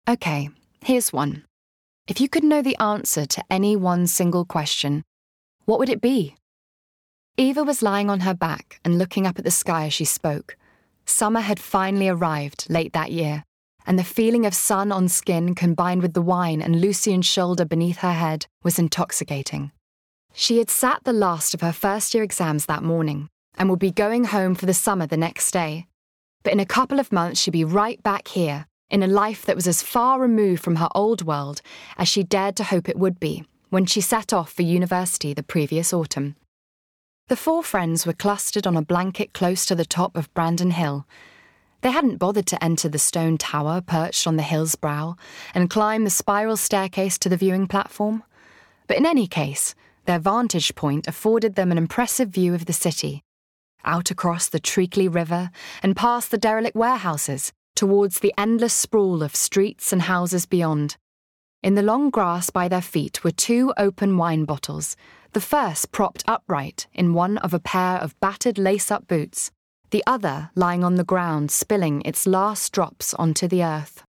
Audiobook Reel
• Native Accent: RP
• Home Studio
She speaks with a cool, confident authority that would be perfect for commercial and corporate projects.